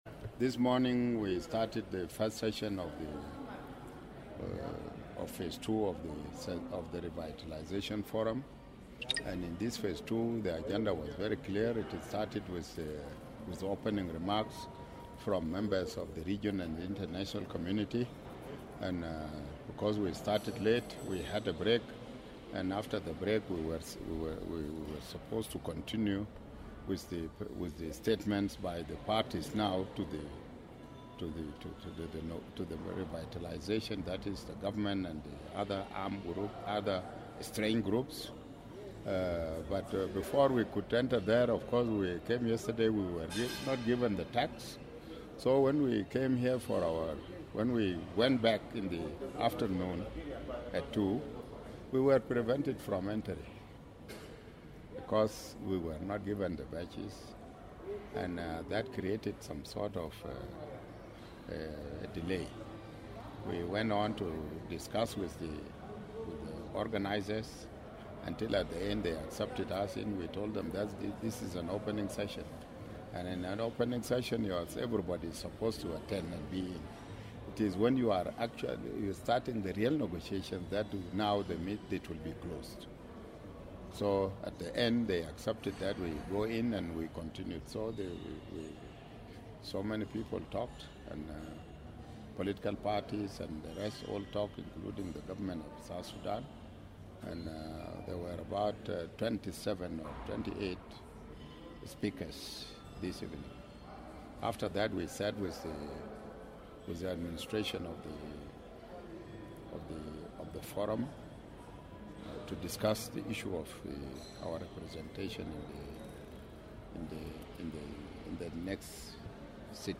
Information Minister Michael Makeui is in Addis Ababa and says the government delegation is strongly represented.